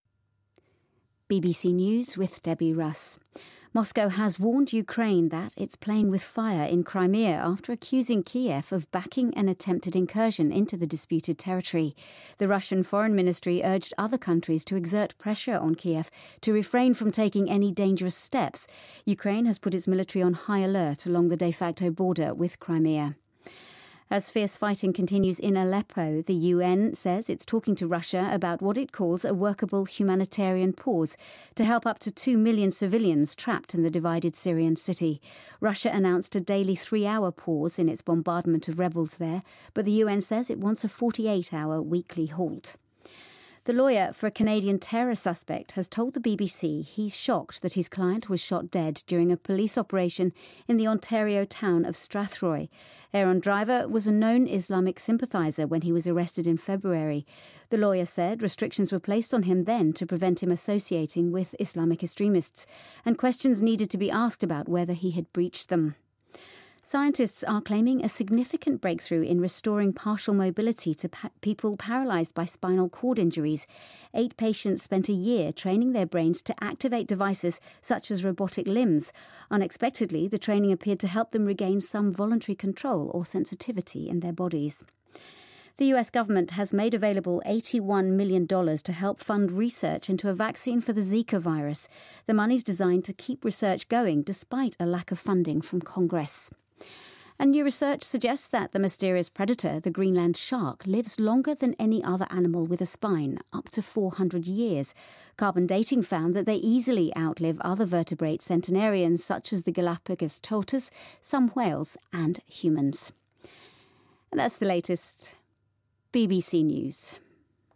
BBC news,科学家称因脊髓受伤瘫痪患者有望恢复行动能力